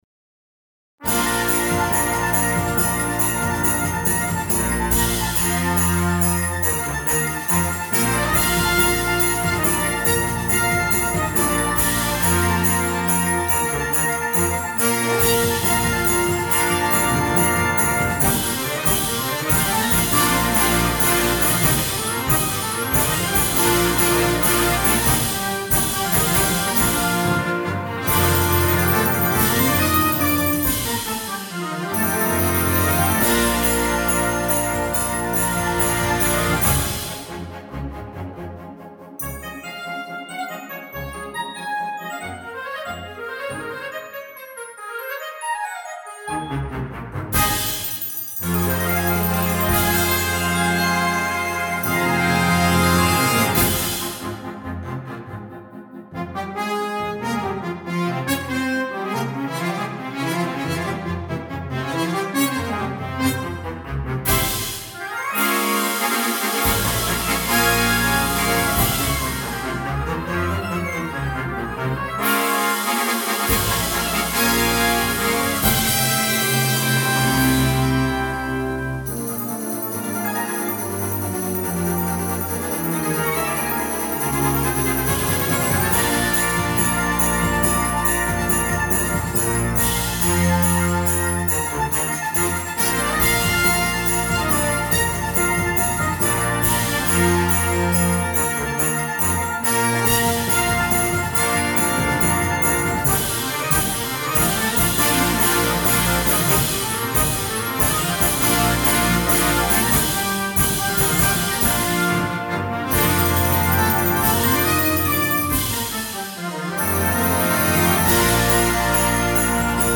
Bb Trumpets 1-2-3
Timpani
Tubular Bells
Concert Wind Band